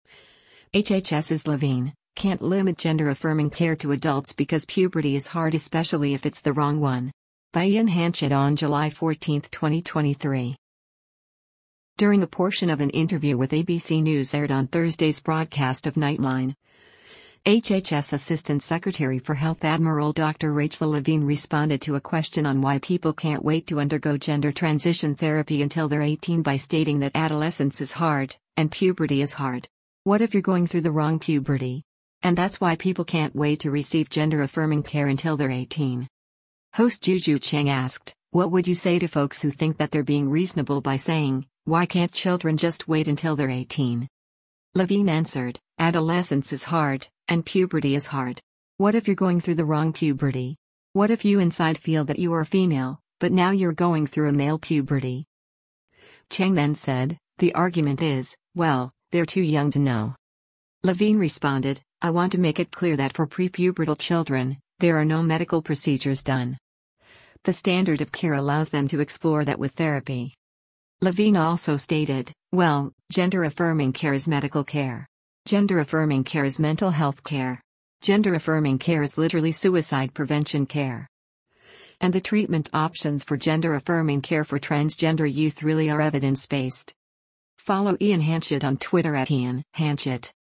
During a portion of an interview with ABC News aired on Thursday’s broadcast of “Nightline,” HHS Assistant Secretary for Health Admiral Dr. Rachel Levine responded to a question on why people can’t wait to undergo gender transition therapy until they’re 18 by stating that  “Adolescence is hard, and puberty is hard. What if you’re going through the wrong puberty?” And that’s why people can’t wait to receive “gender-affirming” care until they’re 18.
Host Juju Chang asked, “What would you say to folks who think that they’re being reasonable by saying, why can’t children just wait until they’re 18?”